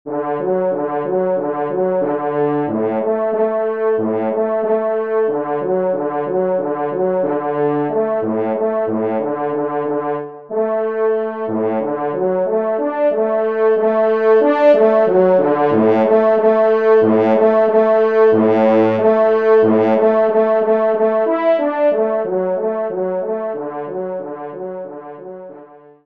Genre :  Divertissement pour Trompes ou Cors
Pupitre 3°  Cor